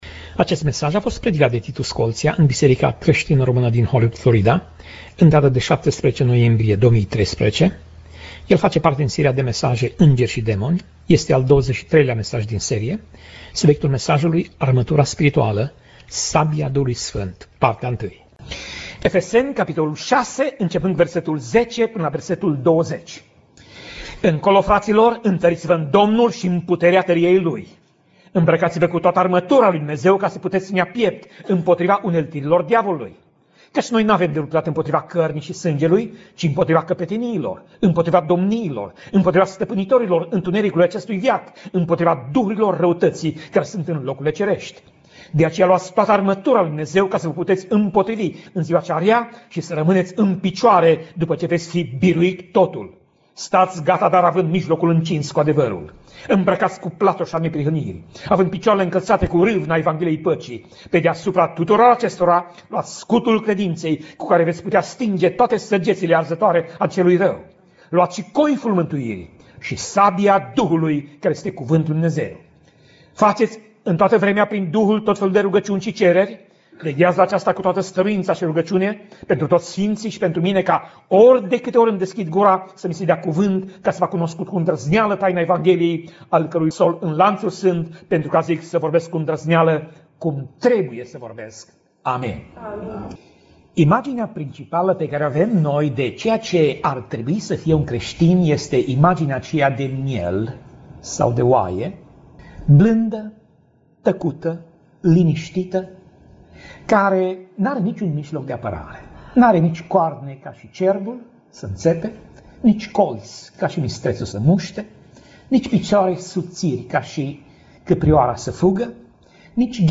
Pasaj Biblie: Efeseni 6:10 - Efeseni 6:20 Tip Mesaj: Predica